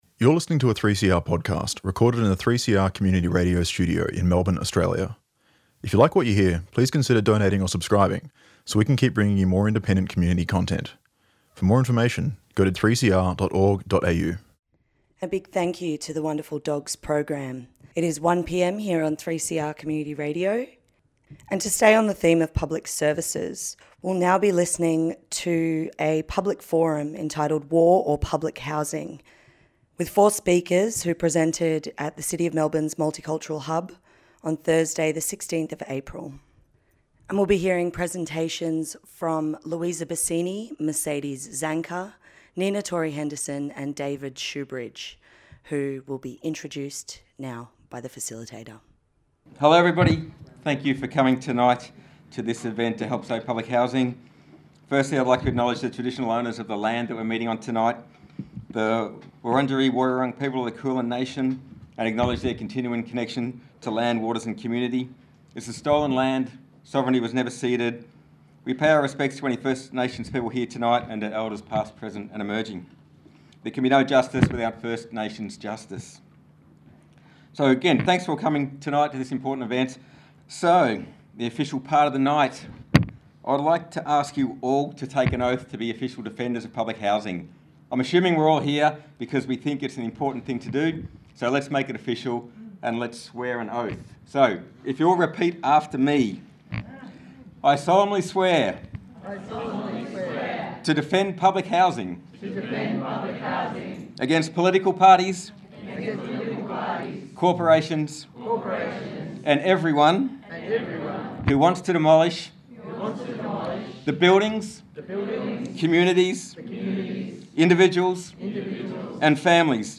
Presentations from the Public Forum entitled 'War Or Public Housing: resist the push to defund public services'
This presentation was held on Thursday 16th of April, at the City of Melbourne's Multicultural Hub, with speakers: